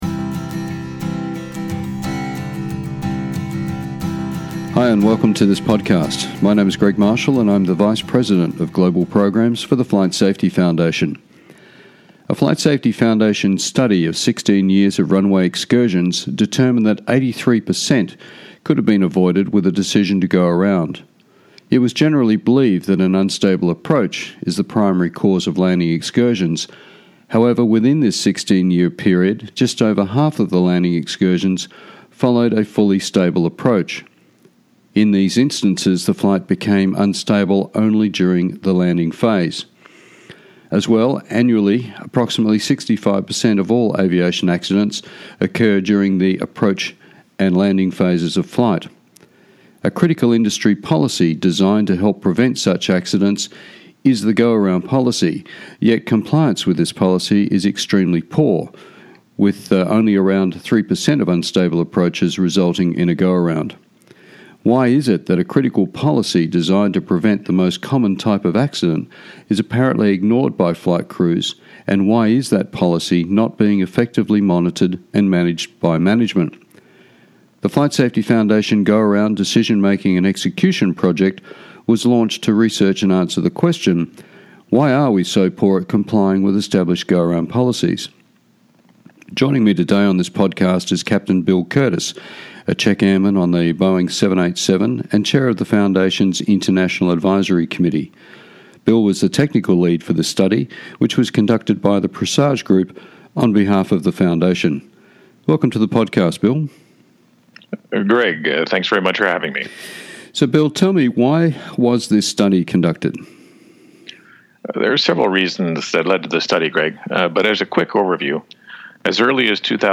Below you will find interviews with safety experts from around the industry on a range of topics of interest to aviation safety professionals.